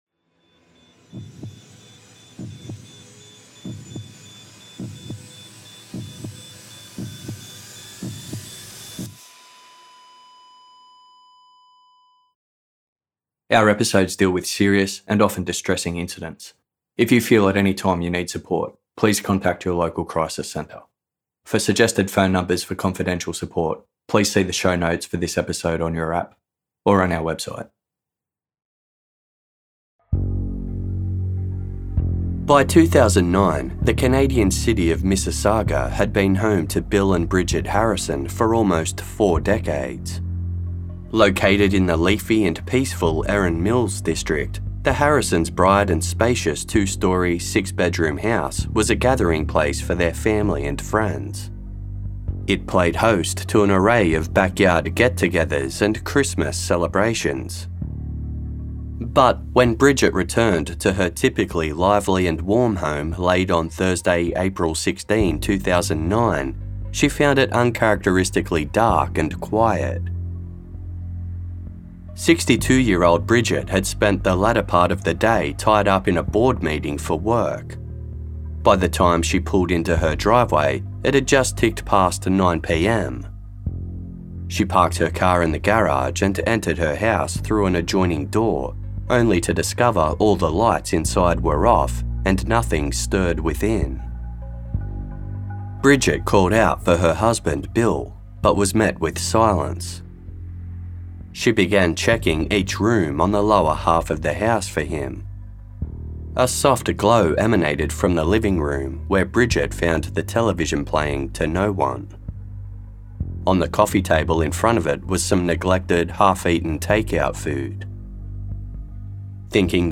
Anonymous Host - narration